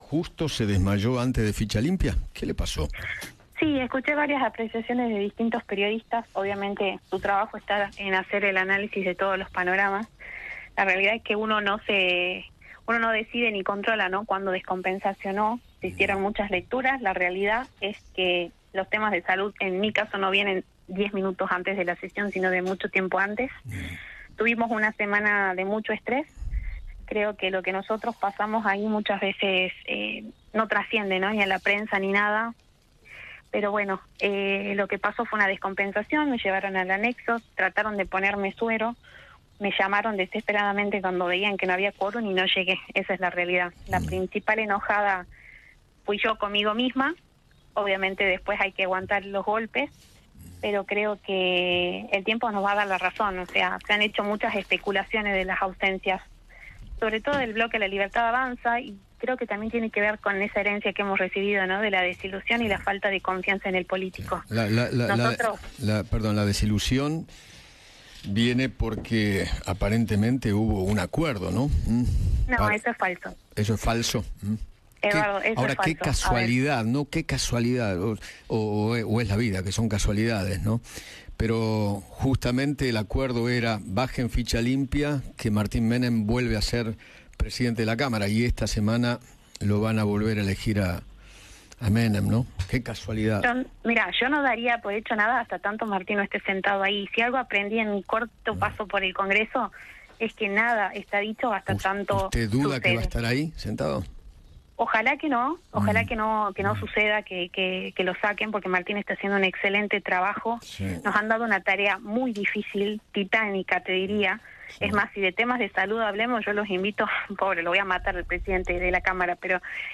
María Emilia Orozco, diputada nacional, dialogó con Eduardo Feinmann sobre el proyecto Ficha Limpia que terminó fracasando al no lograrse el quórum en Diputados.